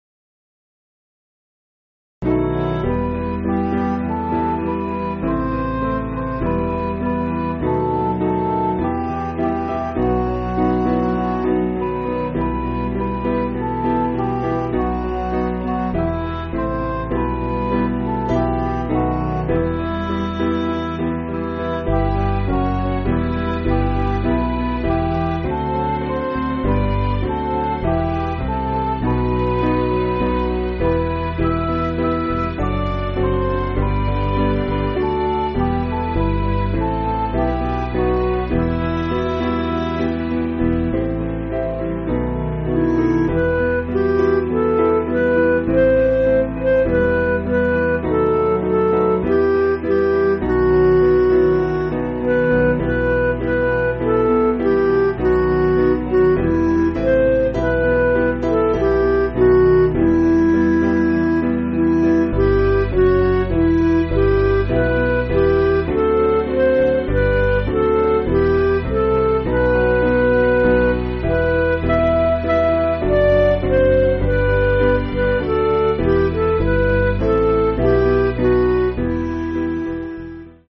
Piano & Instrumental
(CM)   3/Em
Midi